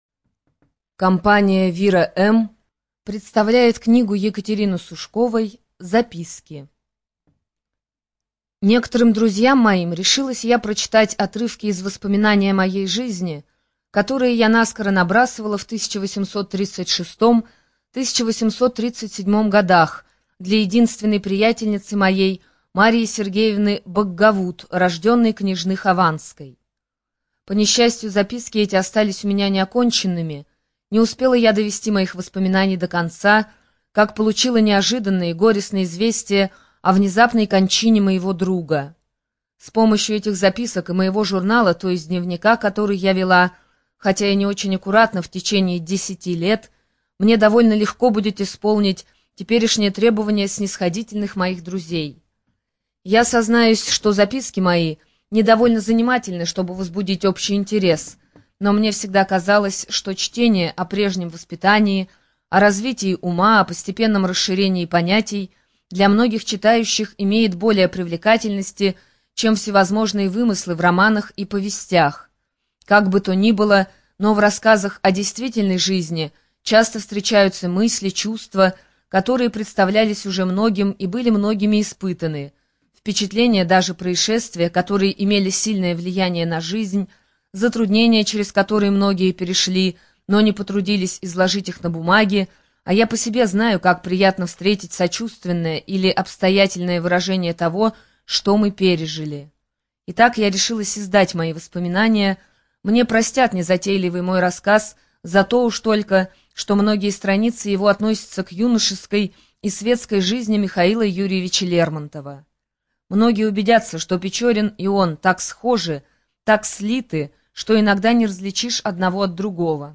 Студия звукозаписиВира-М